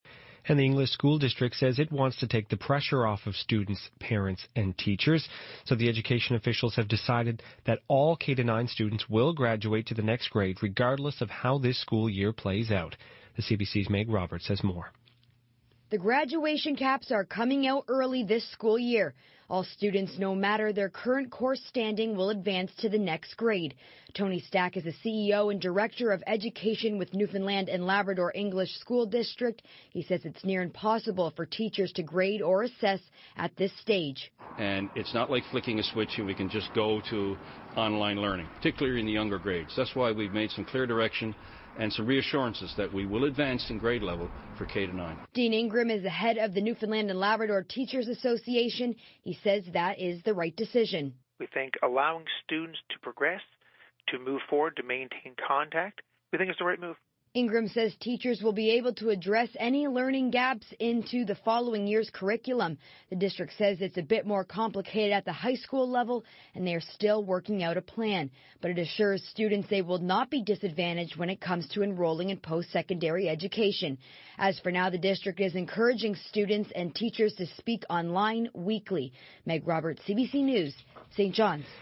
Media Interview - CBC News - March 20, 2020